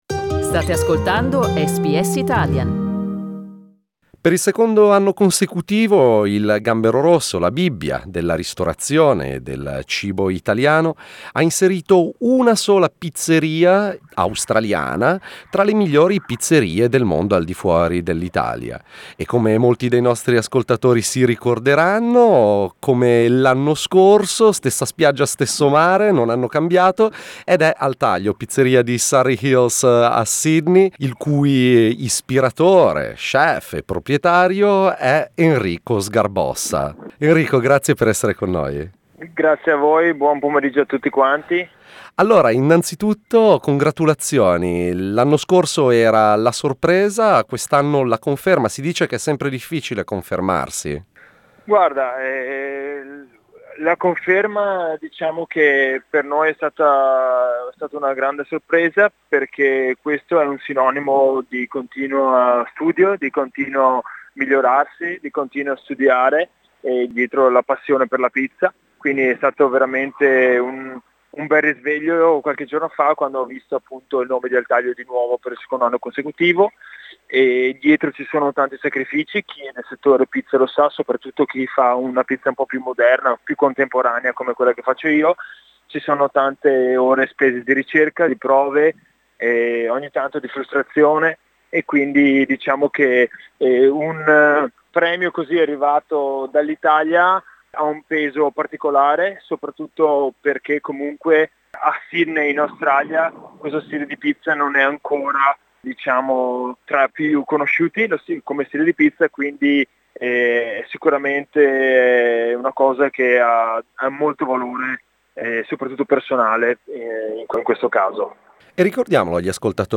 Our interview